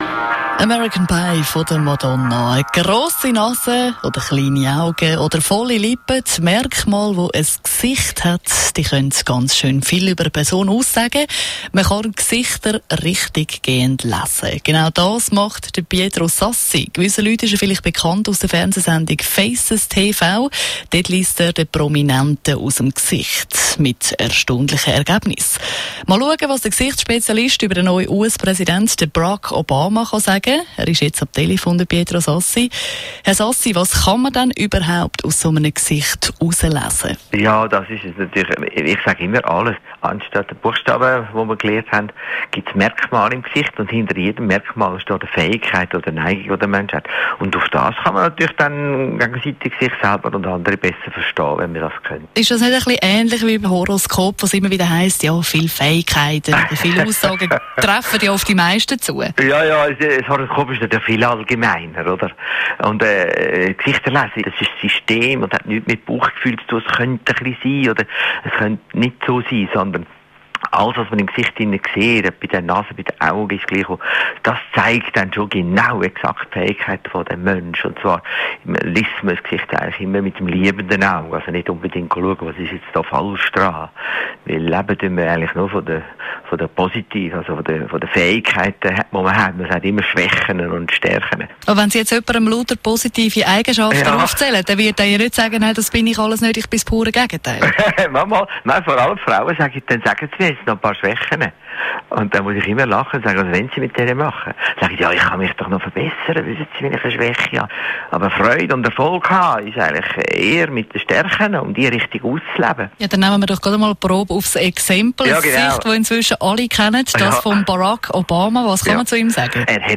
Radio-Interviews